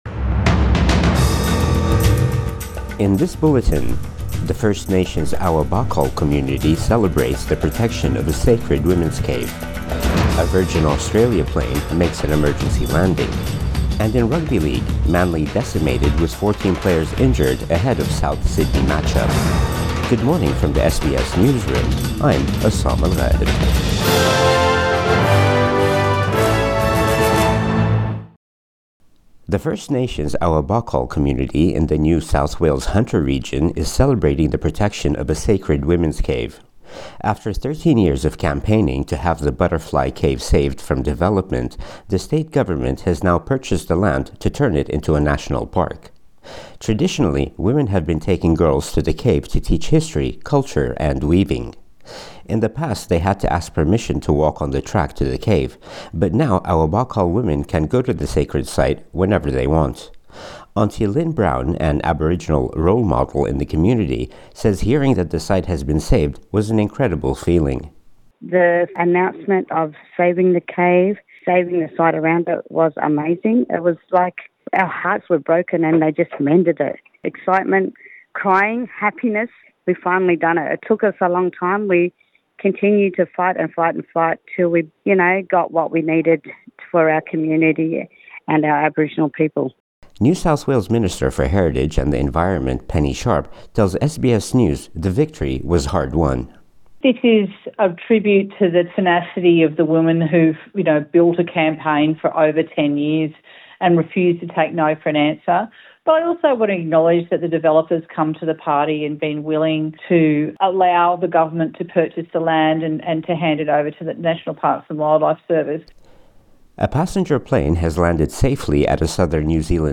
Morning News Bulletin 18 June 2024